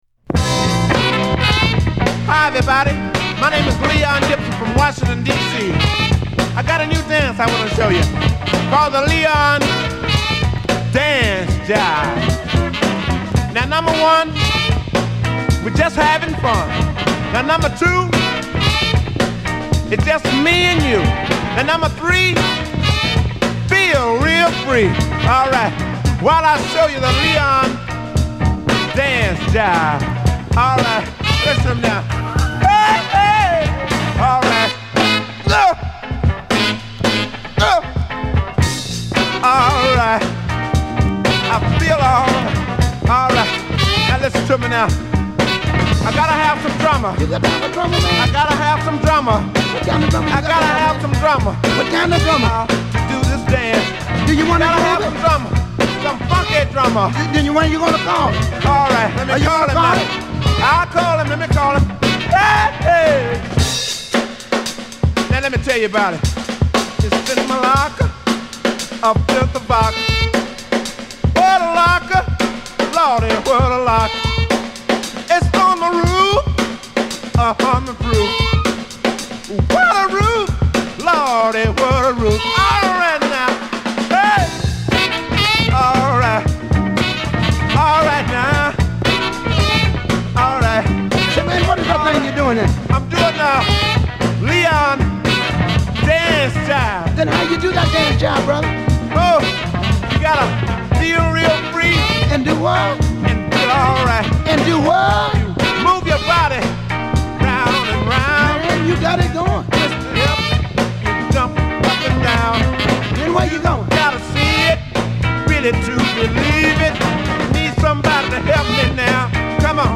'60s & '70s R&B/Soul tracks